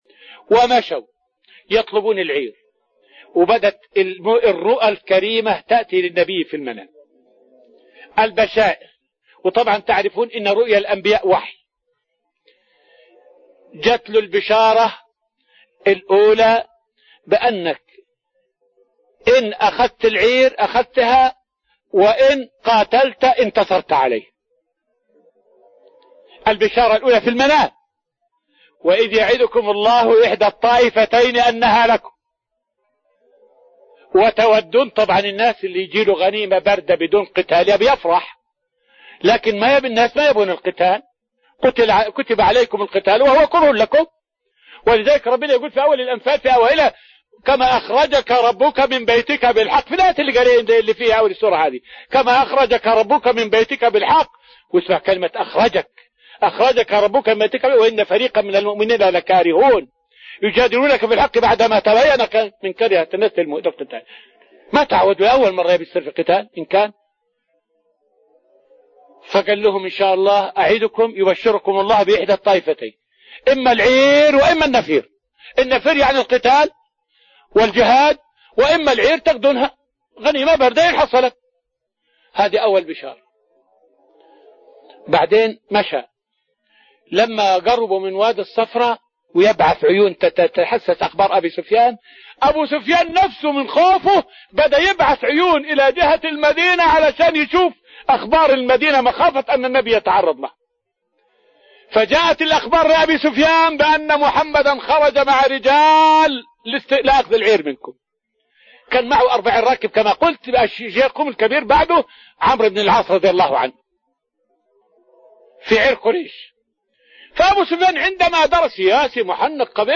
فائدة من الدرس الأول من دروس تفسير سورة الأنفال والتي ألقيت في رحاب المسجد النبوي حول مقتل أمية بن خلف يوم بدر.